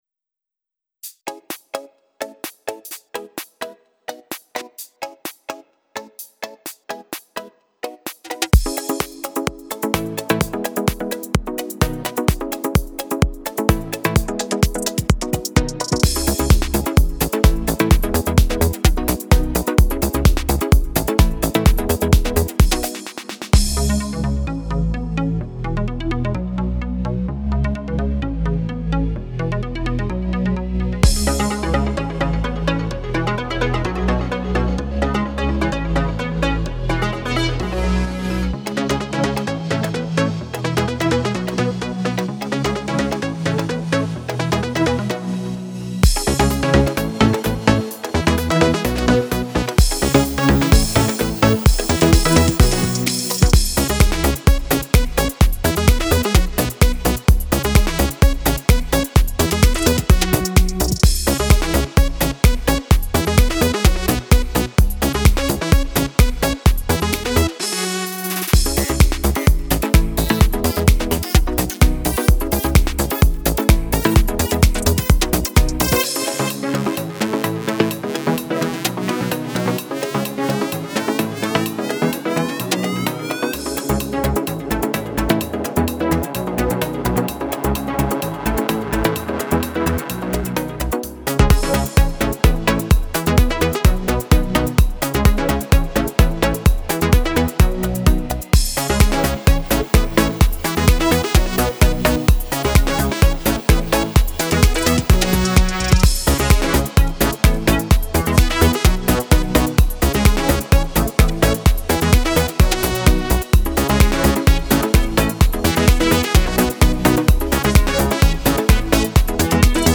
[Диско] топ-хлоп -я-холоп
Был свободный денек и решил потворить. А что сотворил- сам не понял)) Деревенская дискотека?